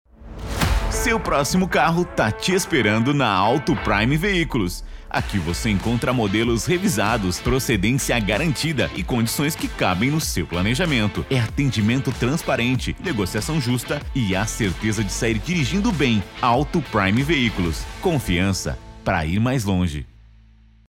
Animada: